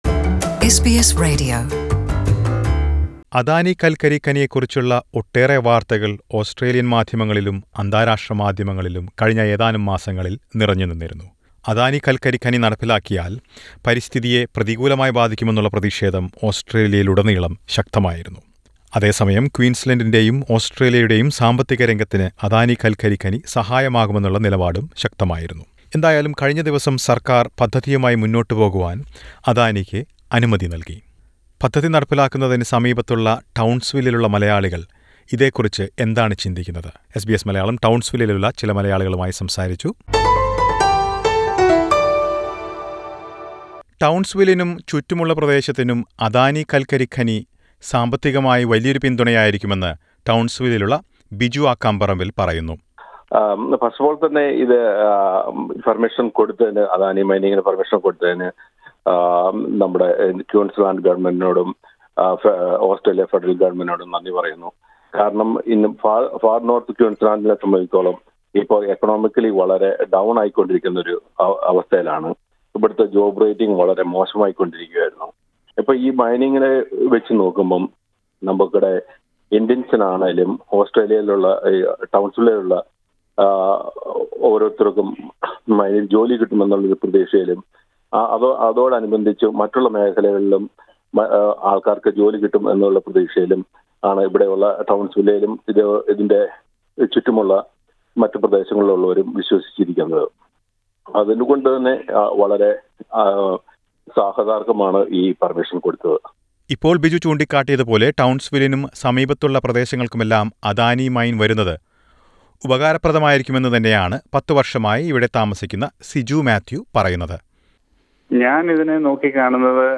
എന്താണ് പദ്ധതിയുടെ സമീപത്തുള്ള ടൗൺസ്‌വില്ലിലുള്ള മലയാളികളുടെ അഭിപ്രായം? ഇവിടെയുള്ള ചിലരുടെ അഭിപ്രായം എസ് ബി എസ് മലയാളം തേടി.